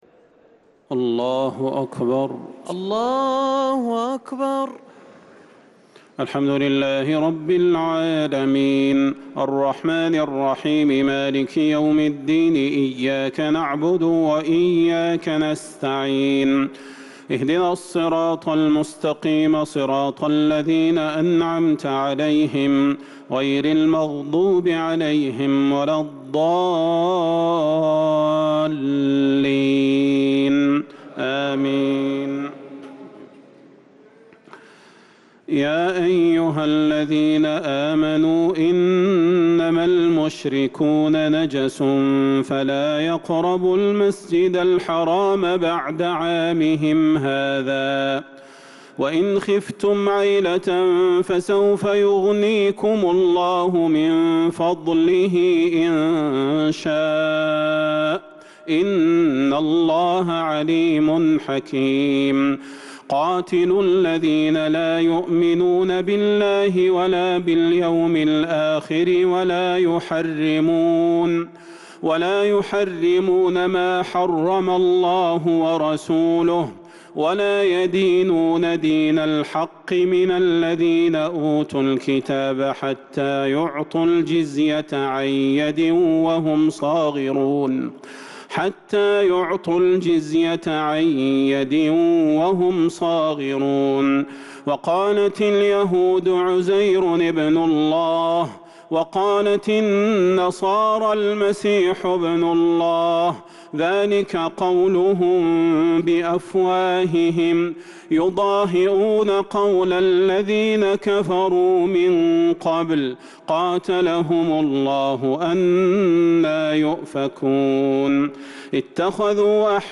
صلاة التراويح ليلة 13 رمضان 1443 للقارئ صلاح البدير - التسليمتان الأخيرتان صلاة التراويح